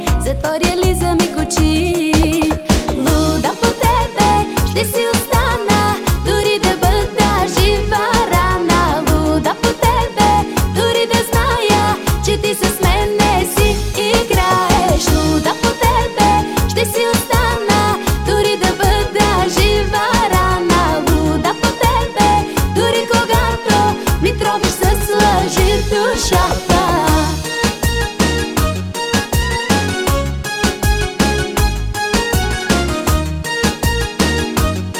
Жанр: Русская поп-музыка / Русский рок / Фолк-рок / Русские